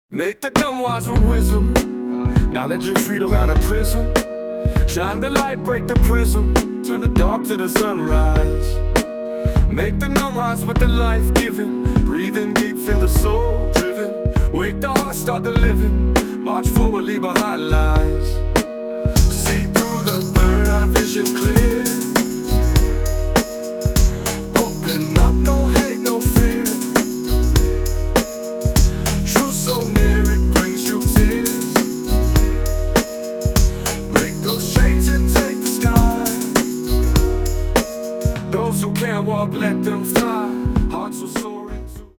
Short version of the song, full version after purchase.
An incredible Hip Hop song, creative and inspiring.